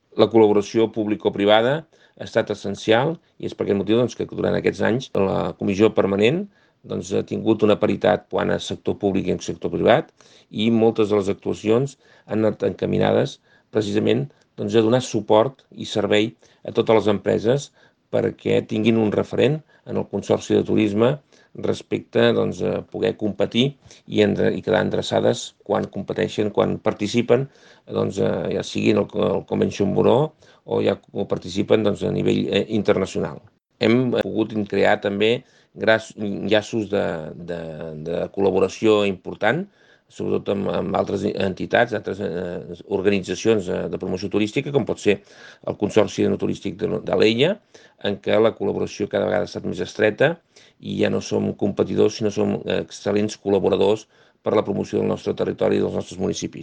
Joaquim Arnó, President Consorci (2015-2023)
Nota de veu Joaquim Arnó - Projectes